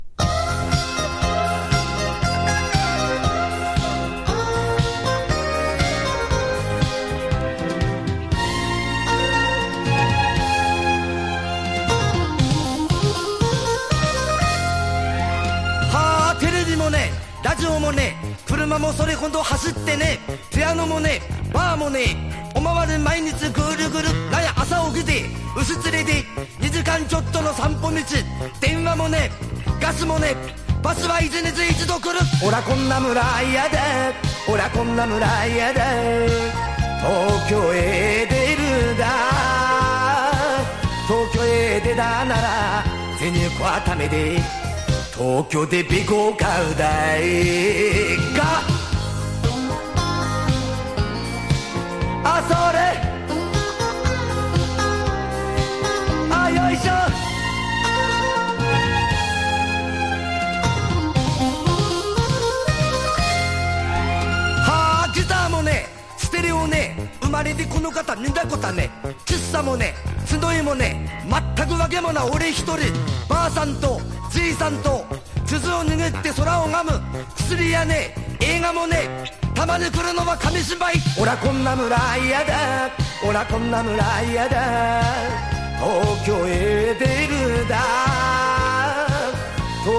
当時としては画期的な韻を踏んだまさかのラップ・テイスト!!
和モノ / ポピュラー